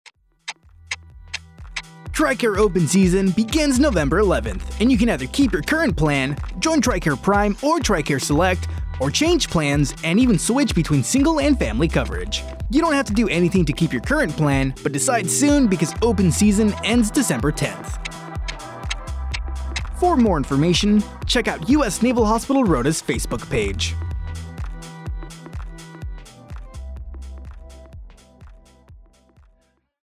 AFN Rota Radio Spot, Tricare Open Season